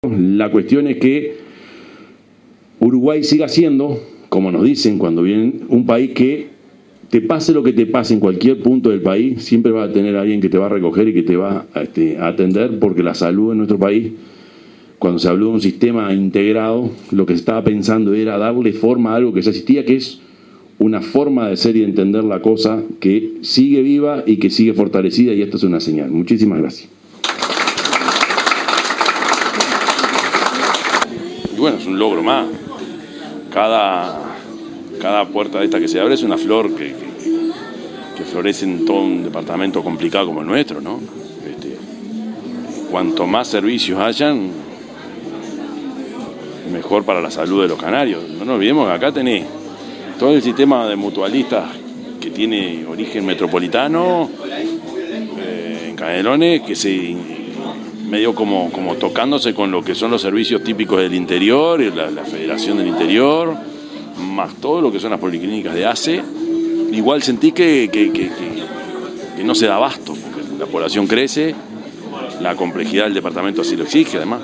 Intendente Orsi participó de la inauguración de nueva policlínica de la Asociación Española en Sauce
intendente_yamandu_orsi.mp3